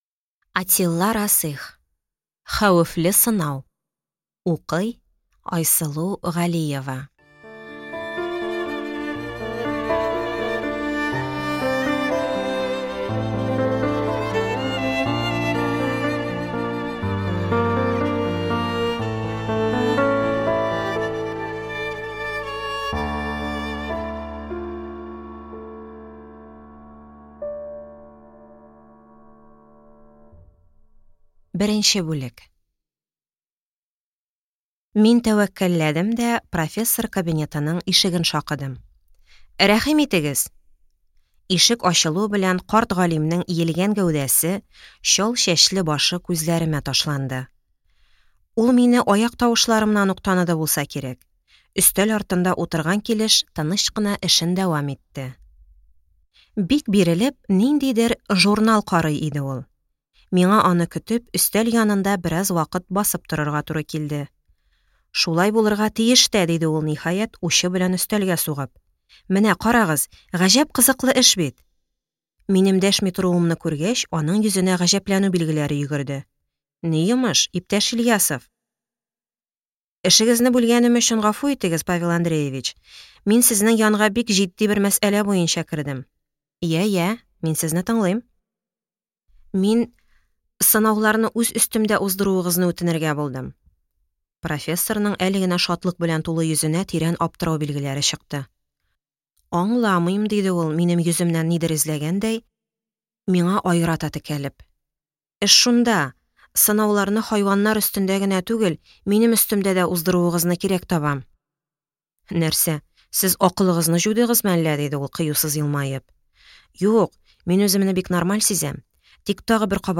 Аудиокнига Хәвефле сынау | Библиотека аудиокниг
Прослушать и бесплатно скачать фрагмент аудиокниги